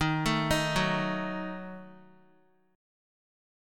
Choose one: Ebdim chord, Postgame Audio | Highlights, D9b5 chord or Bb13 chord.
Ebdim chord